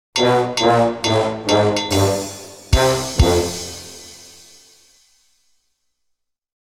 Funny Fail Music Sound Effect
This short and recognizable trombone melody sound effect adds a playful touch to any project. Use it to mark the end of a game level, a game over screen, or a funny fail moment.
Funny-fail-music-sound-effect.mp3